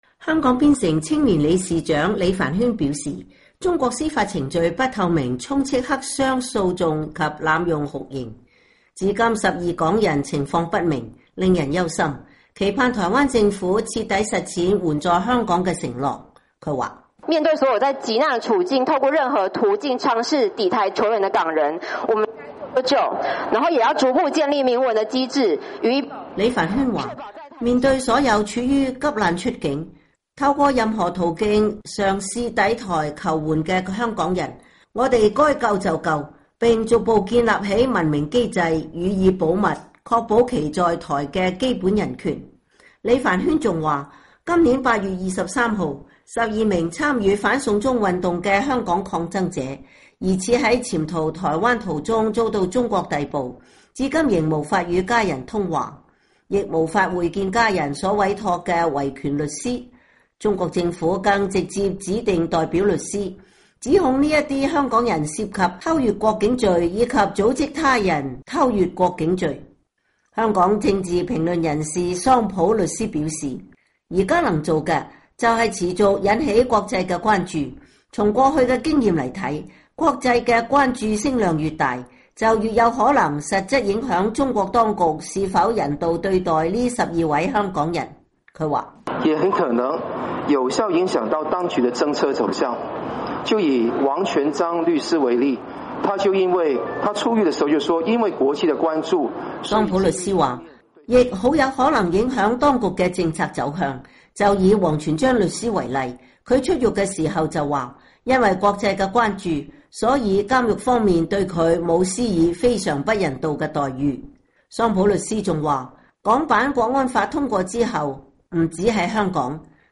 台灣公民、人權團體以及在台港人星期四在香港經濟貿易文化辦事處大樓前舉行記者會，呼籲台灣民眾參加遊行，支持香港爭權民主，聲援遭中國關押的12位香港人士。